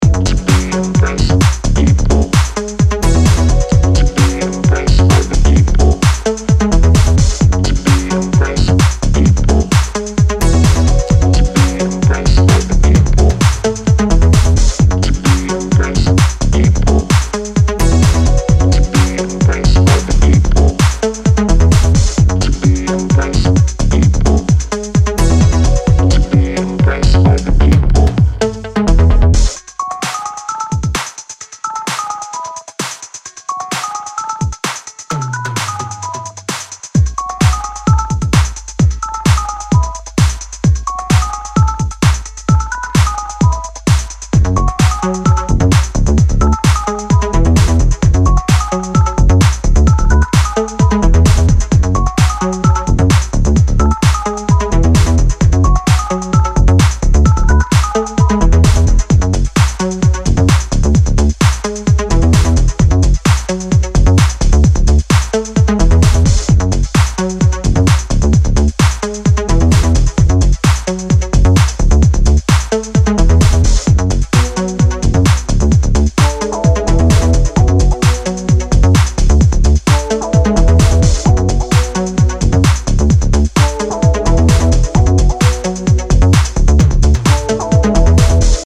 distinctive and hypnotic moods